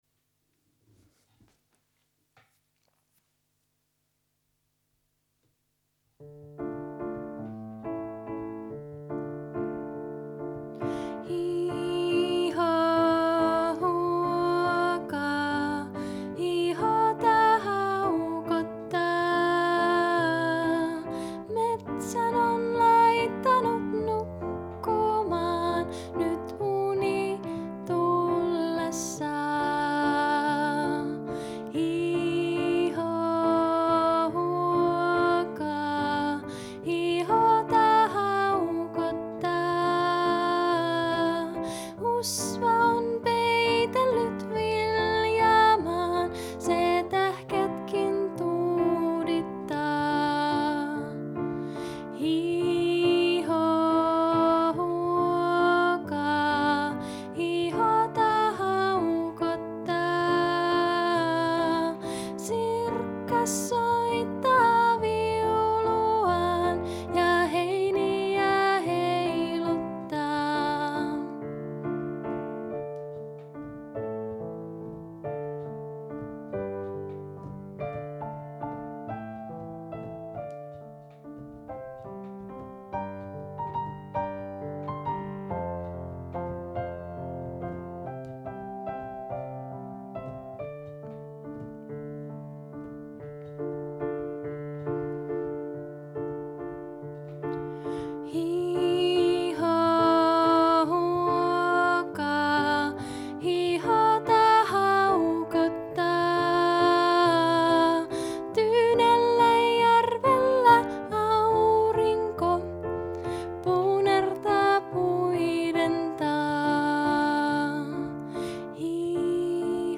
Olen äänittänyt lauluni laulaen ja soittaen joko 5-kielisellä kanteleella tai pianolla. Säveltämäni kappaleet ovat sävelletty myötäillen suomalaista kansanmusiikkiperinnettä, ja niissä on vivahteita länsimaalaisesta populaarimusiikista.